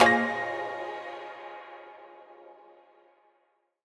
menu-edit-click.mp3